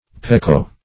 Meaning of pekoe. pekoe synonyms, pronunciation, spelling and more from Free Dictionary.